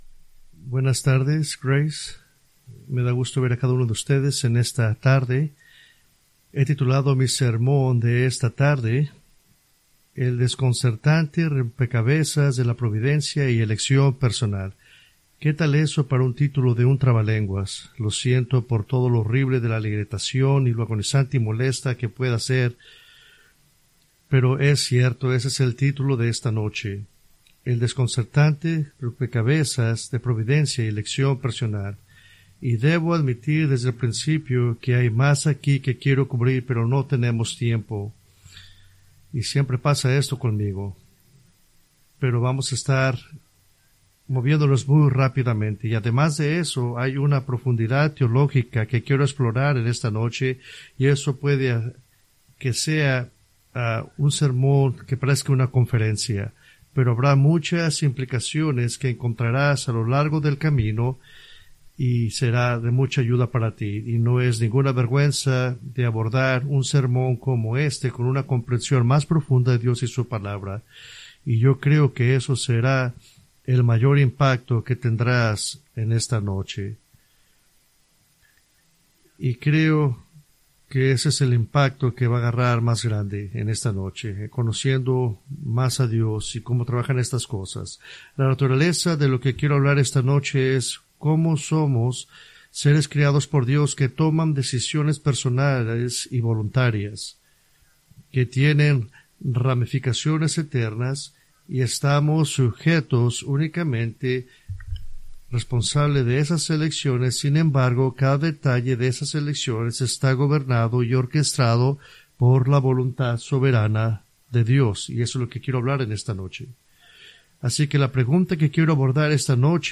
fecha: 28 Apr, 2024 groupo: Sermones varios 2024 mas: Download MP3 | iTunes